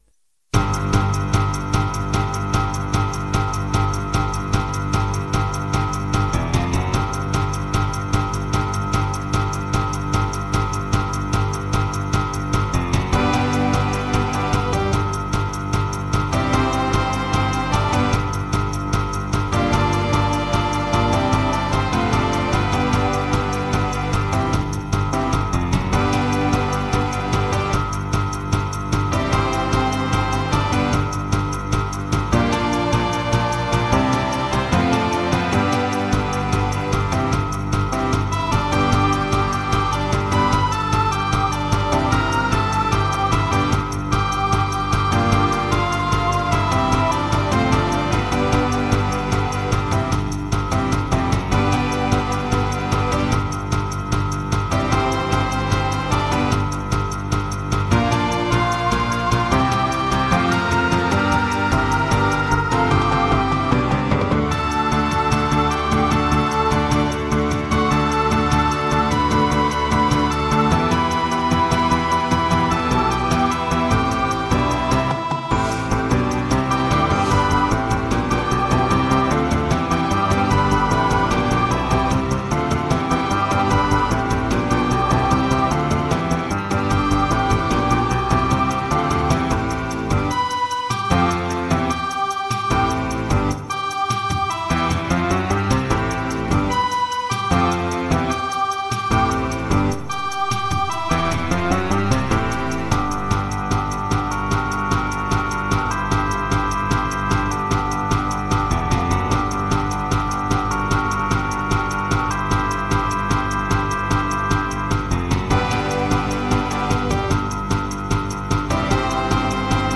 Moderato-Rock